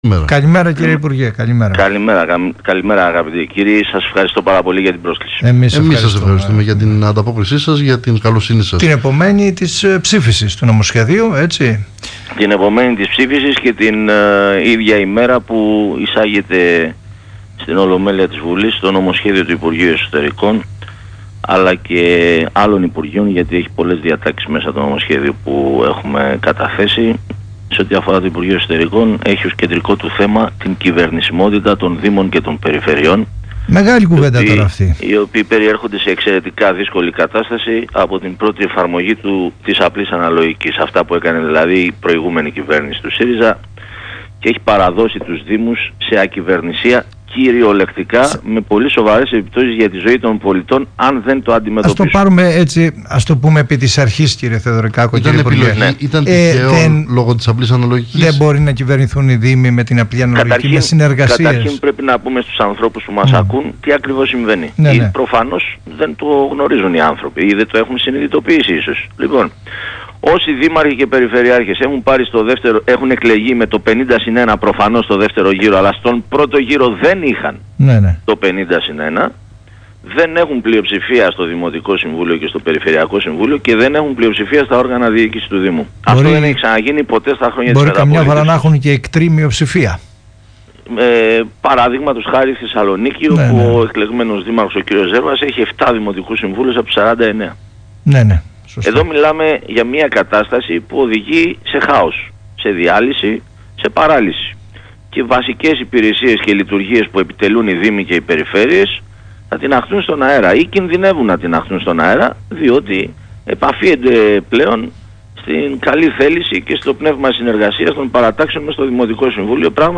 Συνέντευξη του ΥΠΕΣ στο Θέμα Radio 104,6